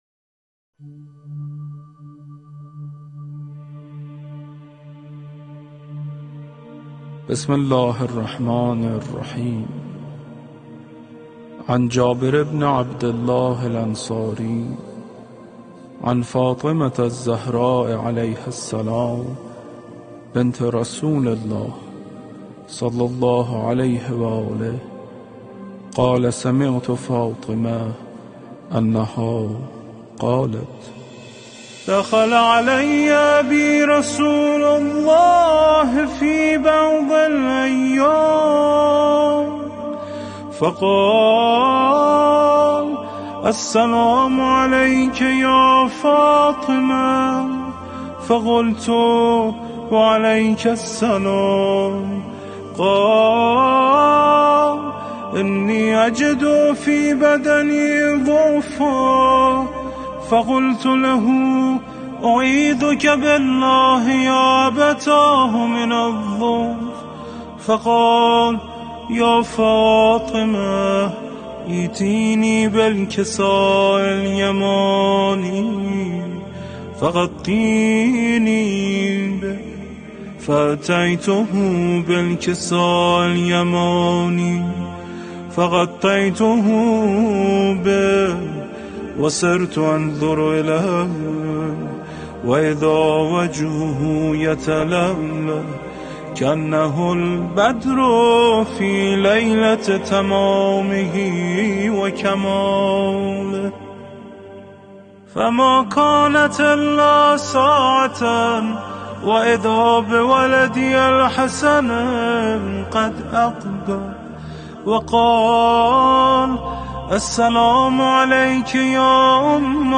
دانلود حدیث کسا صوتی با صدای علی فانی
hadis-al-kisa-ali-fani.mp3